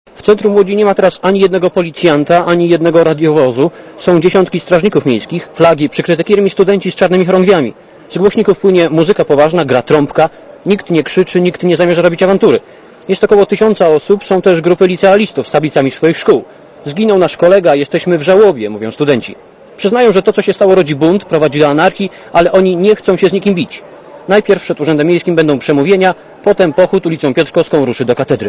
Relacja reportera Radia ZET
marszlodz.mp3